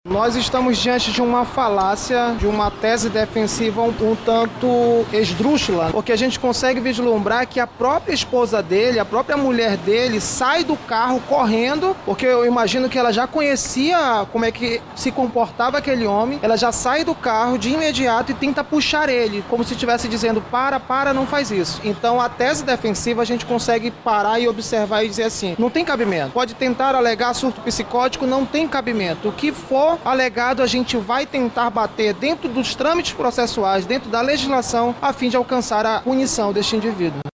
A entrevista coletiva ocorreu nessa segunda-feira 09/12, na sede da Delegacia Geral da Polícia Civil do Amazonas, localizada na zona Centro-Oeste de Manaus.